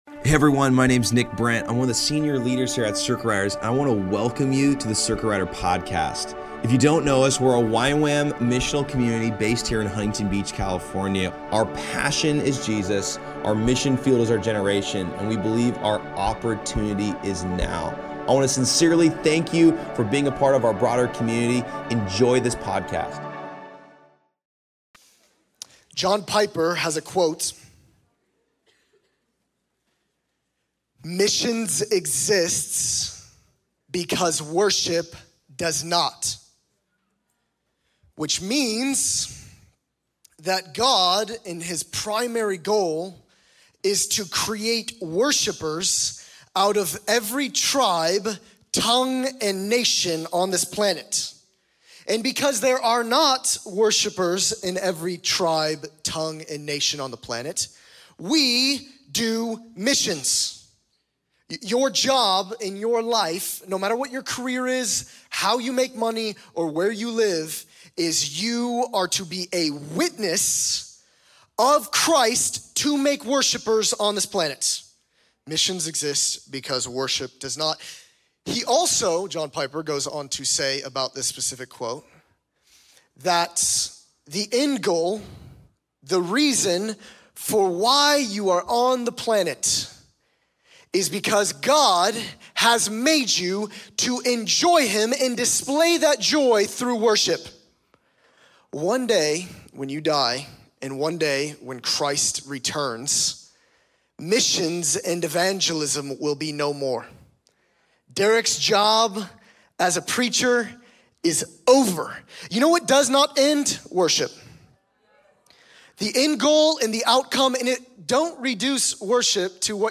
Circuit Riders Monday Night in Costa Mesa CA on February 3rd 2025